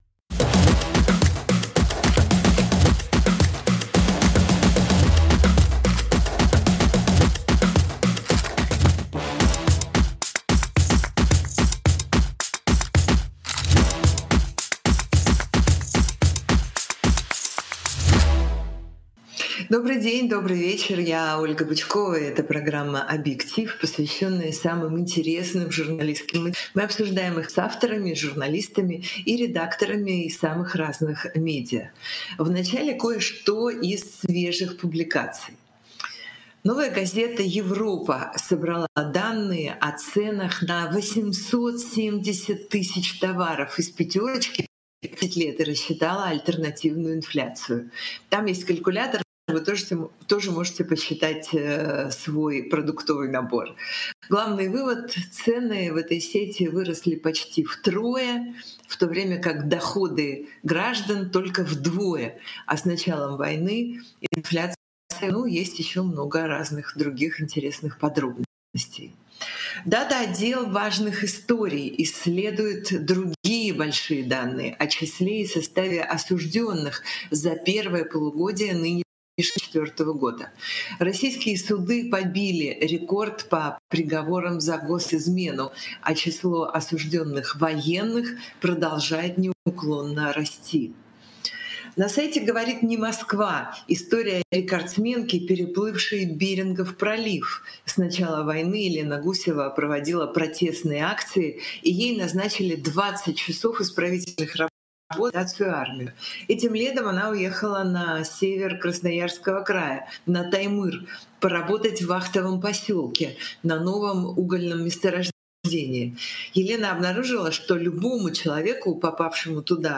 Ведет программу Ольга Бычкова